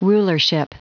Prononciation du mot rulership en anglais (fichier audio)
Prononciation du mot : rulership